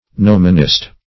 Gnomonist \Gno"mon*ist\, n. One skilled in gnomonics.
gnomonist.mp3